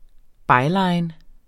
Udtale [ ˈbɑjˌlɑjn ]